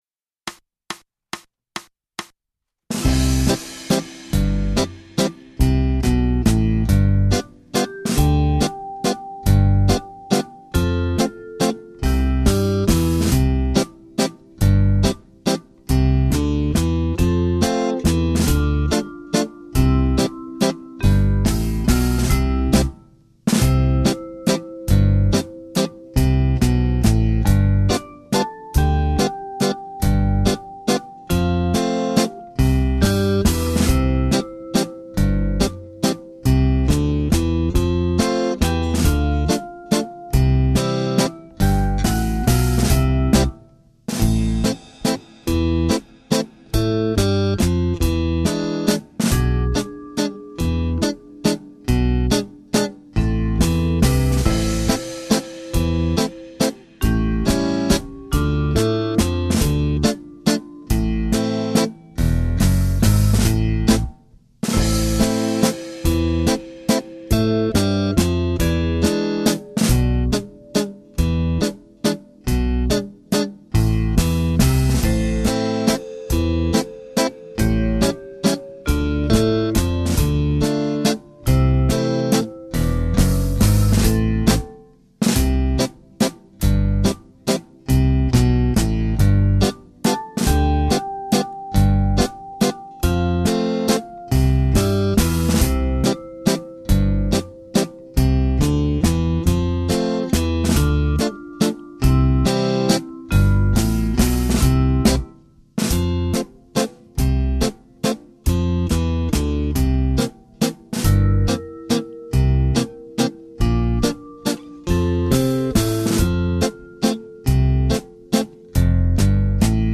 Genere: Mazurka
Scarica la Base Mp3 (2,96 MB)